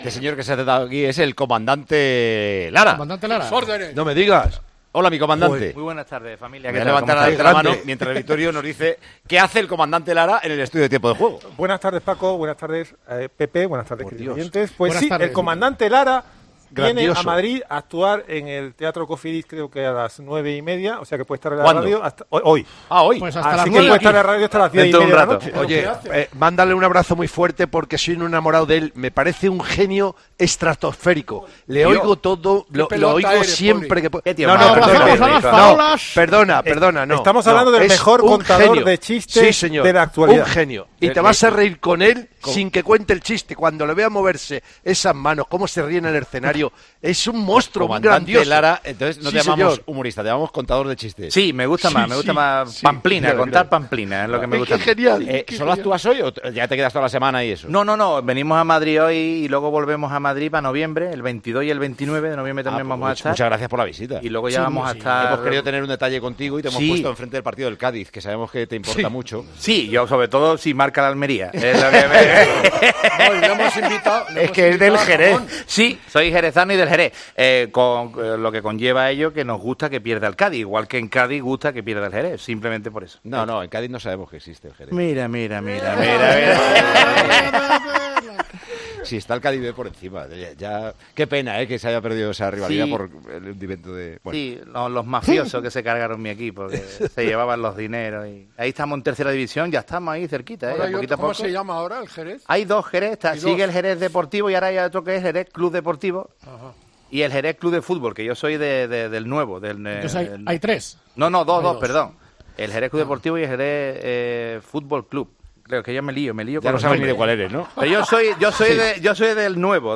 Los chistes y la bulería del Comandante Lara, en Tiempo de Juego